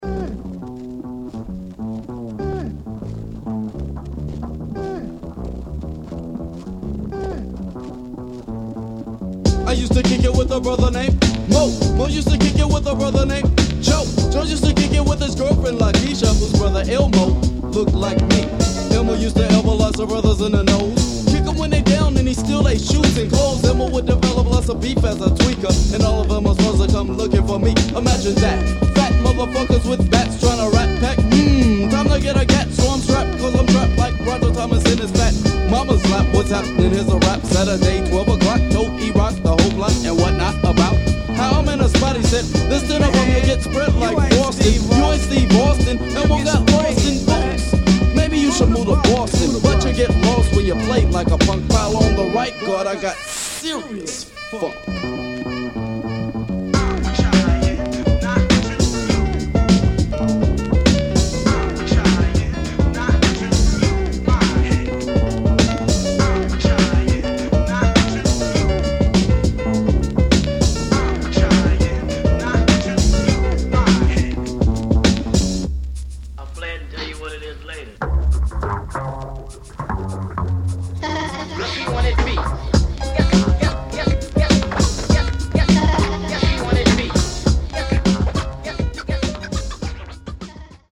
The debut LP from the left-wing rappers from Oakland.
Funk